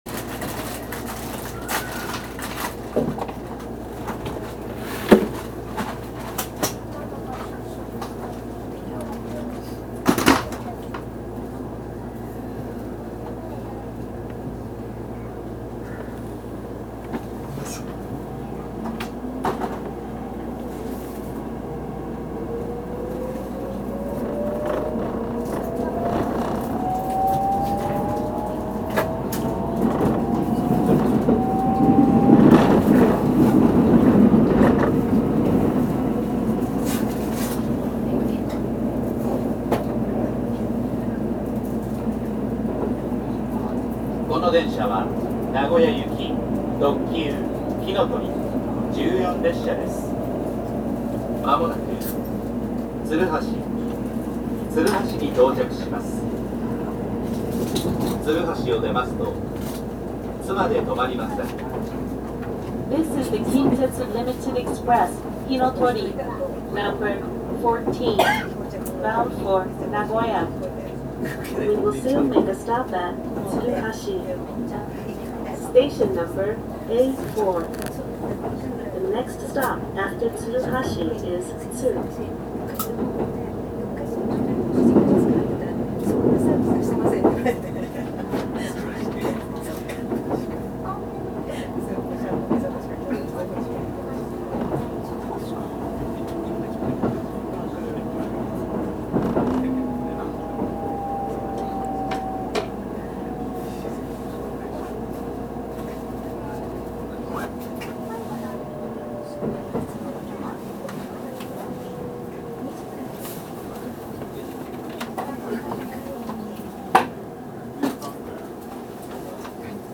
走行音
録音区間：大阪上本町～鶴橋(ひのとり14列車)(お持ち帰り)